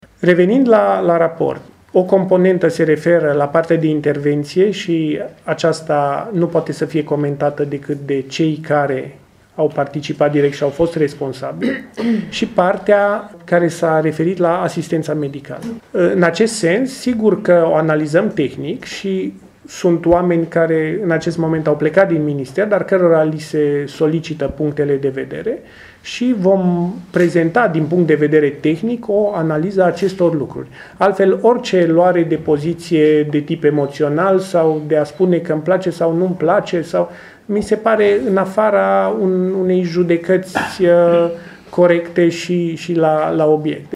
Patriciu Achimaș Cadariu a declarat astăzi la Tîrgu-Mureș că în prezent se lucrează la o analiză tehnică asupra componentei medicale în cazul Colectiv, iar aceasta, indiferent de rezultat, va fi asumată de către Minister.